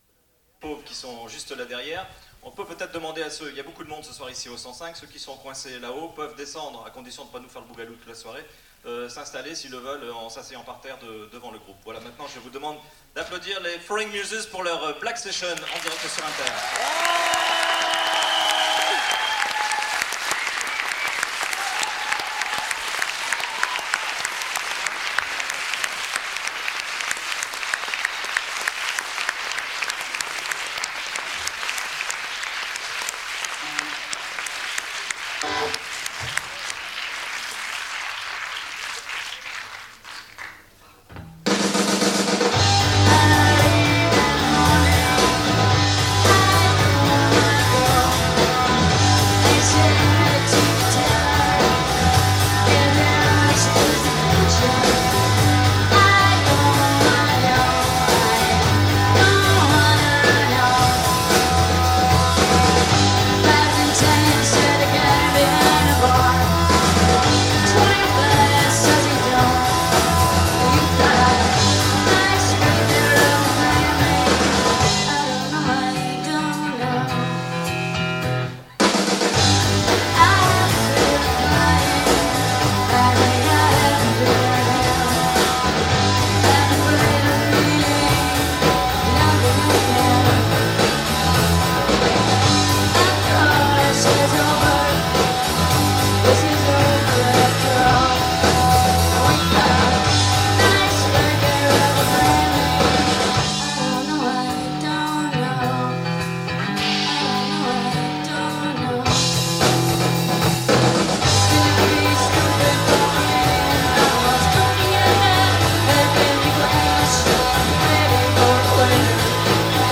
Studio 105, Paris
independent American Music